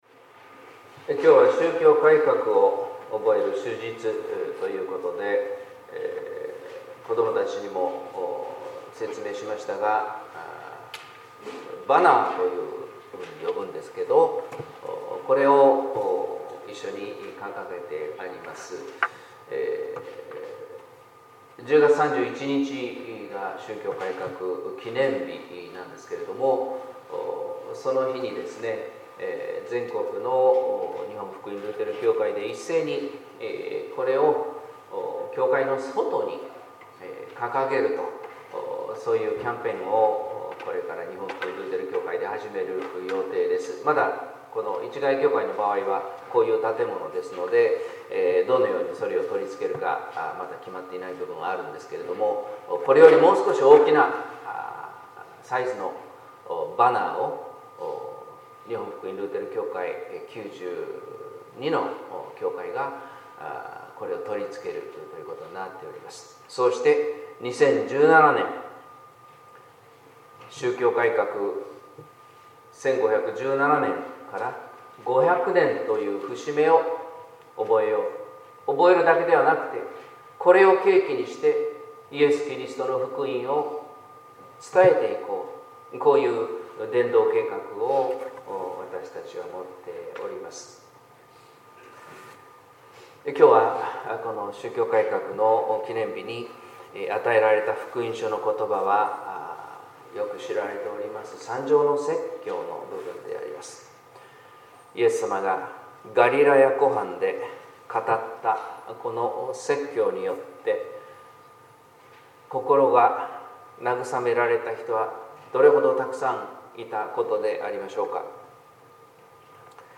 説教「愛の神は義なる神」（音声版）